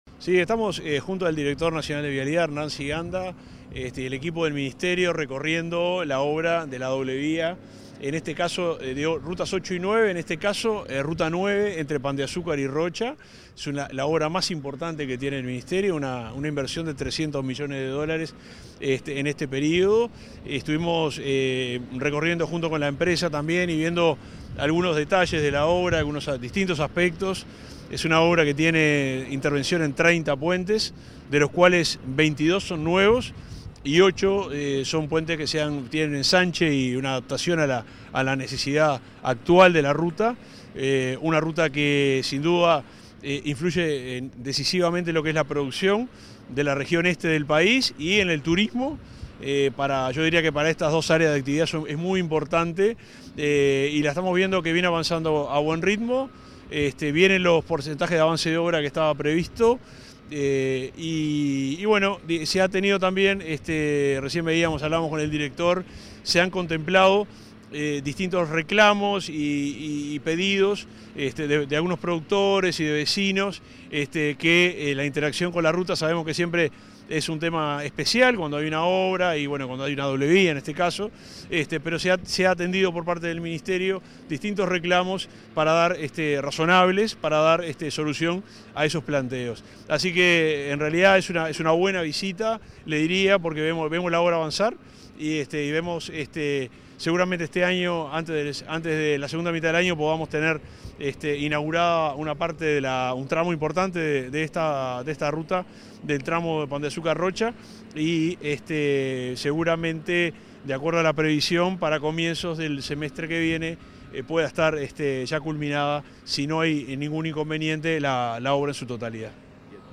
En diálogo con Comunicación Presidencial, el ministro interino de Transporte y Obras Públicas, Juan José Olaizola, y el director nacional de Vialidad,